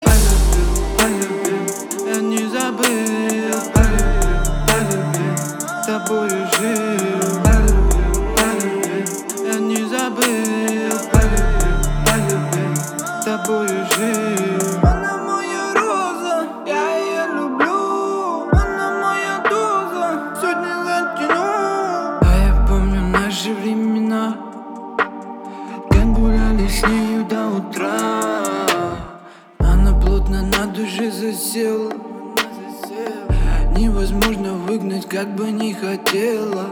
• Качество: 320, Stereo
мужской вокал
лирика
красивый мужской голос
русский рэп